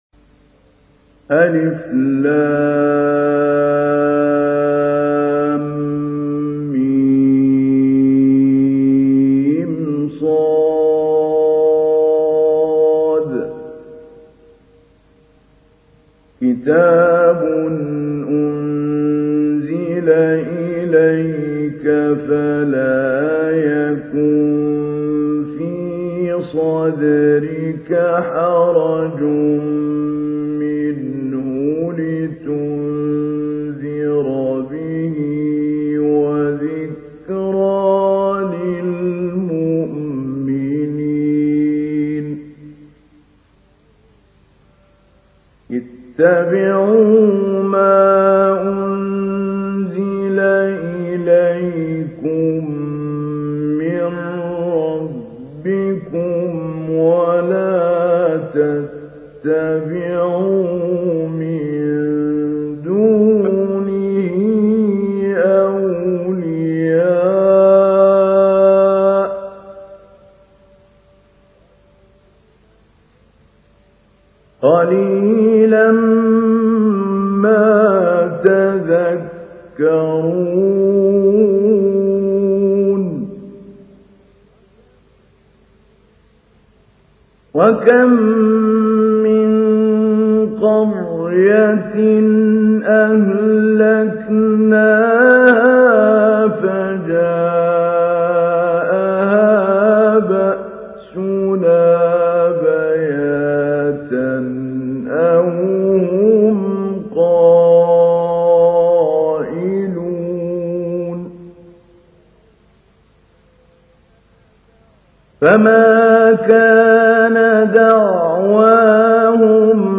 تحميل : 7. سورة الأعراف / القارئ محمود علي البنا / القرآن الكريم / موقع يا حسين